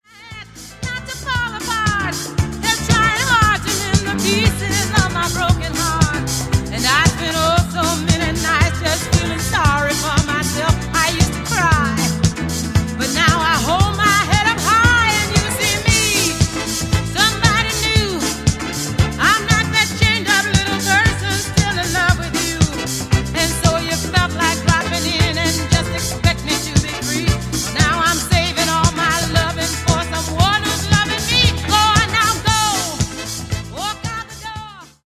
Genere:   Disco Funk